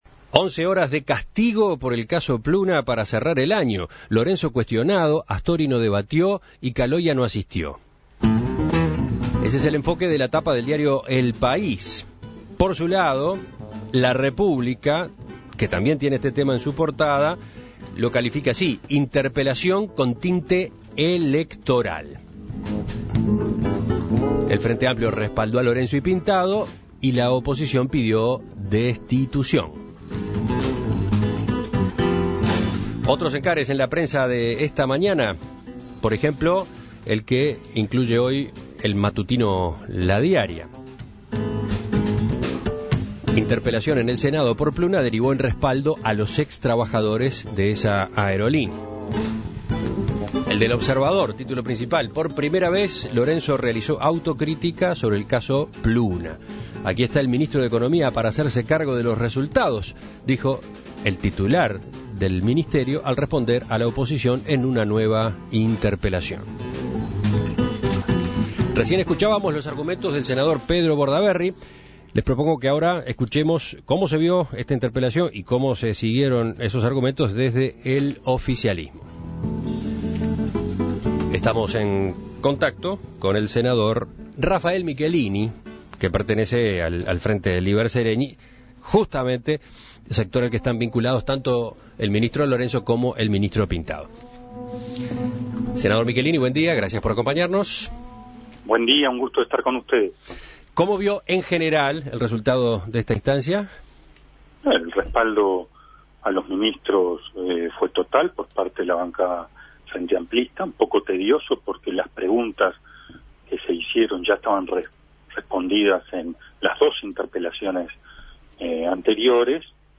La interpelación a los ministros de Economía y de Transporte, Fernando Lorenzo y Enrique Pintado, no arrojó nuevas resoluciones con respecto a la liquidación de Pluna. Para conocer la visión del Frente Líber Seregni, sector al que pertenecen dichos ministros, En Perspectiva dialogó con Rafael Michelini.